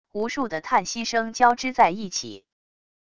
无数的叹息声交织在一起wav音频